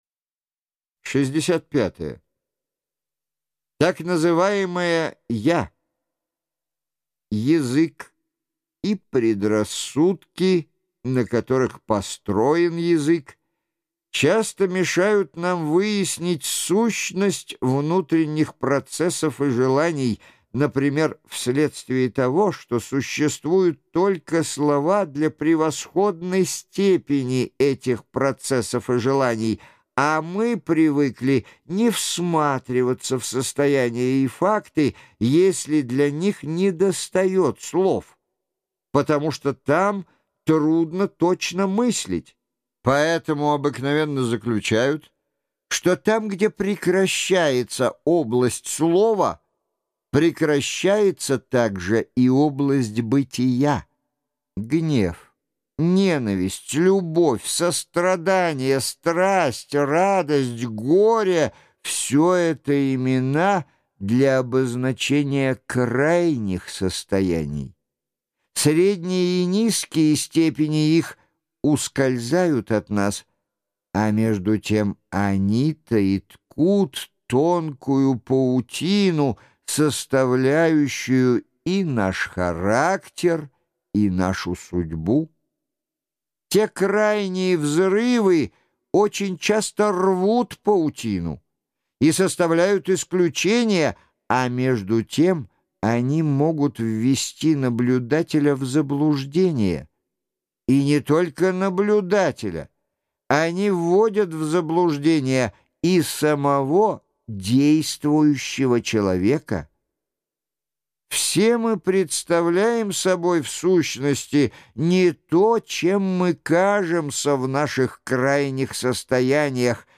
Жанр: Аудиокнига.